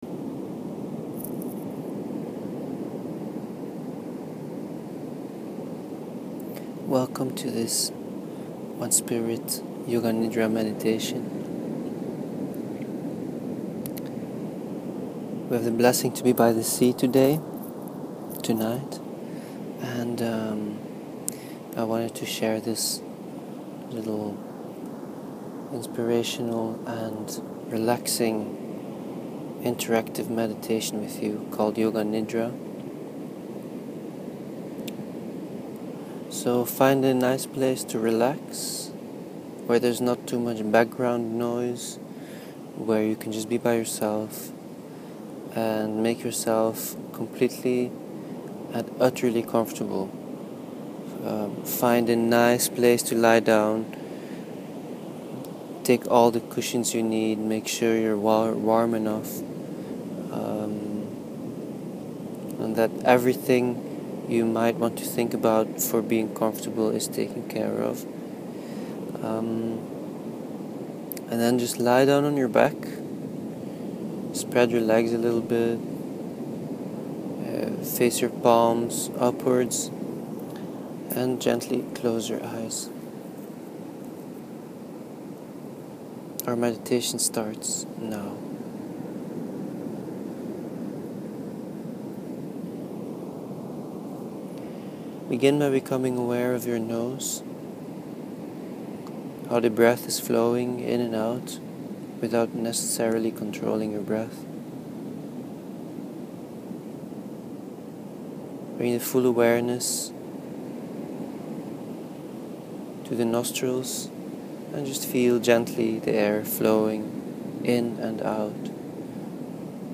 Find here a short recording straight form the beach of Boca del Cielo, Chiapas, Mexico for you to enjoy and relax. This is a 22-minute Yoga Nidra Meditation. Yoga Nidra is a powerful and healing Yogic technique that dives into your subconscious and allows you to relax deeply.
Seaside yoga nidra.mp3